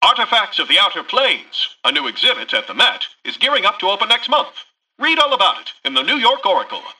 Newscaster_headline_24.mp3